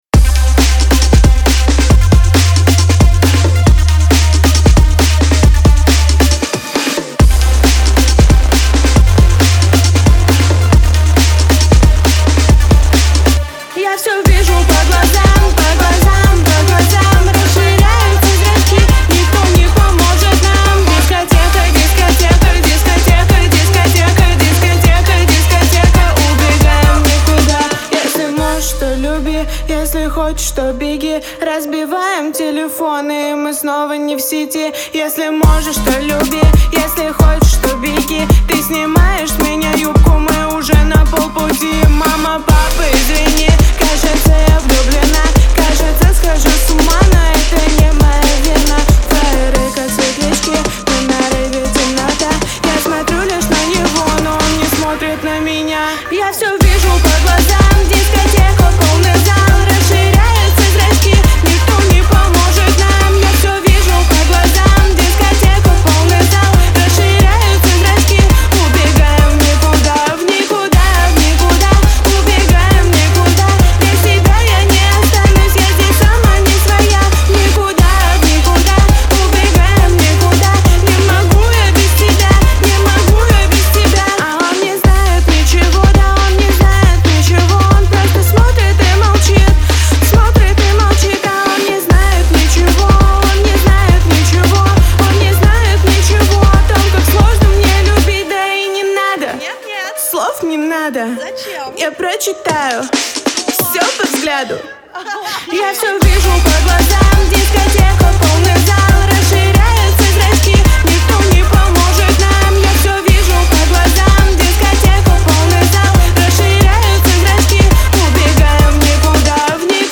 это трек в жанре поп-рэп